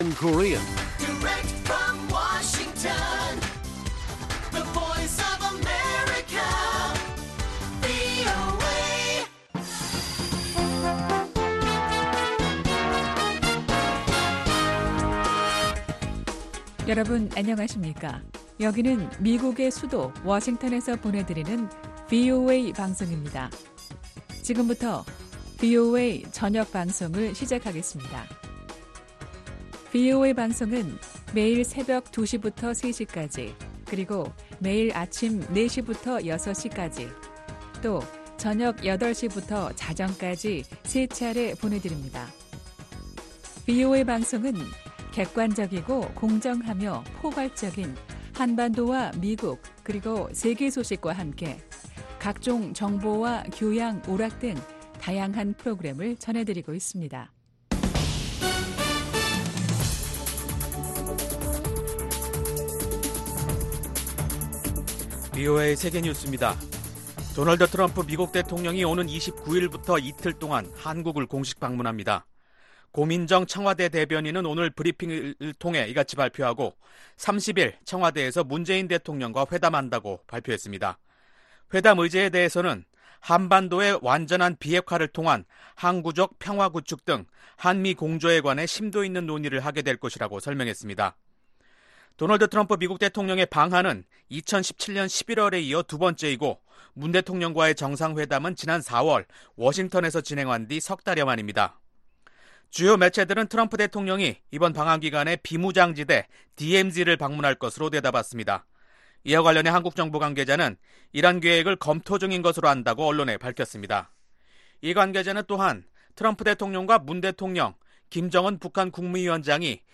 VOA 한국어 간판 뉴스 프로그램 '뉴스 투데이', 2019년 6월 21일 1부 방송입니다. 마이크 폼페오 미 국무장관은 북한만 준비가 된다면 실무협상을 당장 시작할 수 있다고 말했습니다. 미국과 북한은 완전한 비핵화를 합의하되 이행은 단계적으로 하는 접근법이 비핵화 협상을 진전시킬 것이라고 1990년대 옛 동구권 국가들의 비핵화 지원을 주도한 샘 넌 전 상원의원이 말했습니다.